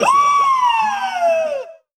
02-Whoooo.wav